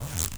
ZIPPER_Short_1_mono.wav